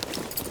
tac_gear_17.ogg